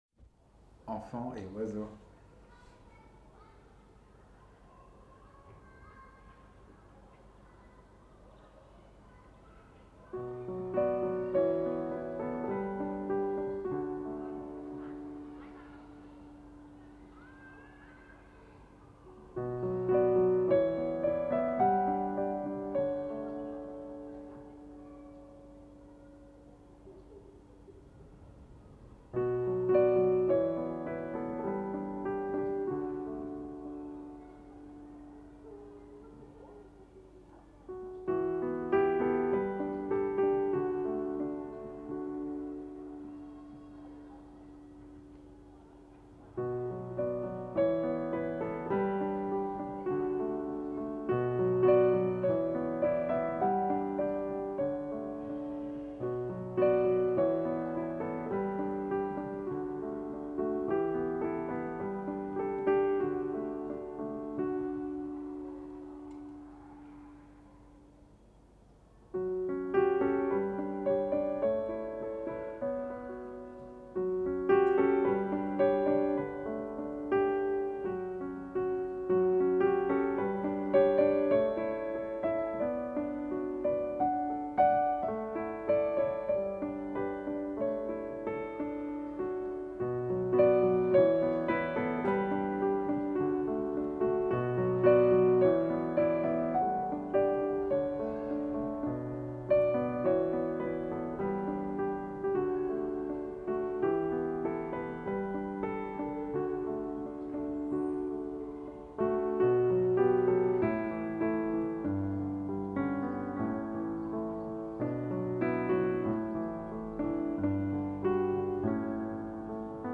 Enfants et Oiseaux, 2025, Sound composition (piano and field recordings), 2 min 36 sec
A piano composition recorded live at my window along sounds of kids playing and birds chirping on a summer day in Vienna. For me, these are sounds of innocence, peacefulness, and nostalgia.